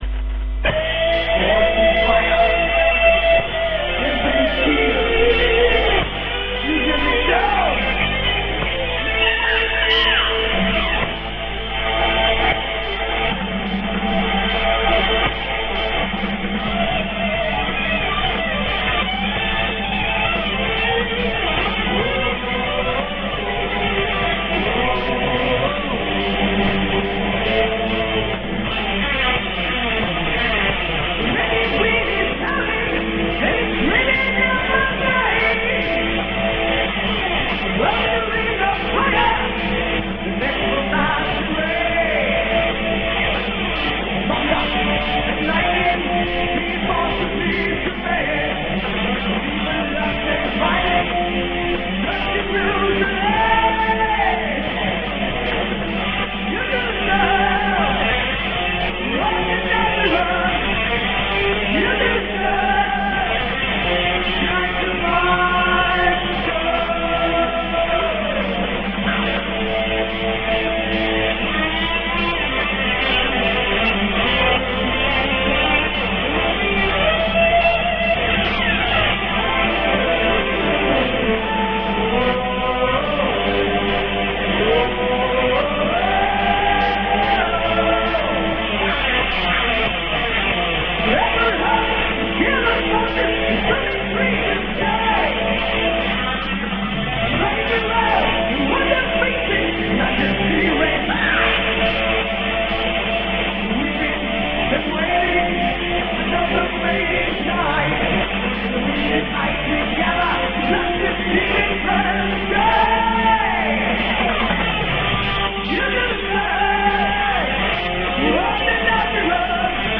vocals, keyboard
guitar
bass guitar
drums